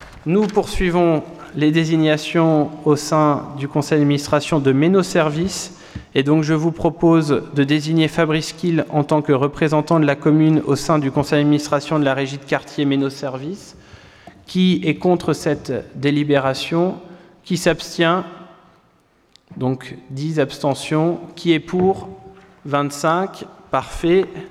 Point 21 : Désignation d’un représentant au conseil d’administration de Meinau Services Conseil Municipal du 04 juillet 2020